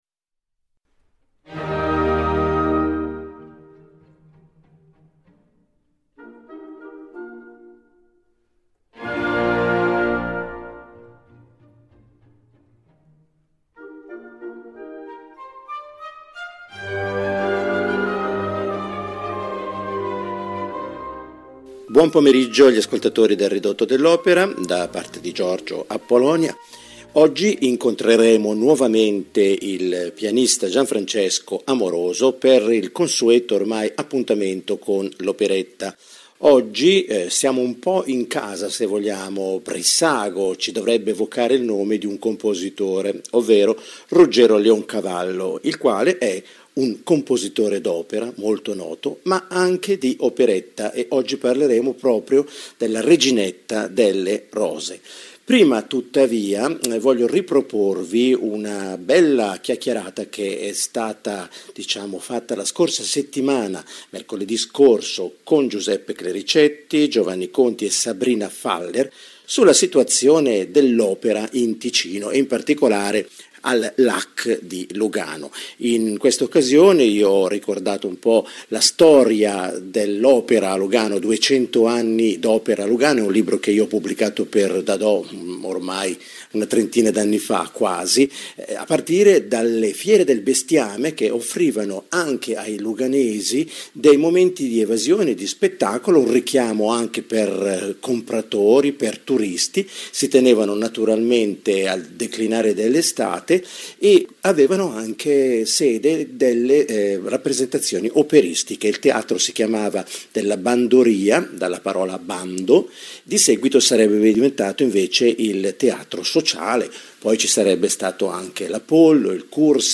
Nella pregevole edizione che viene proposta per gli ascolti una "regina" del palcoscenico: il soprano Lina Pagliughi che presta uno dei timbri più luminosi e immacolati del teatro d'opera al genere "minore" dell'operetta. Quale il destino dell'operetta?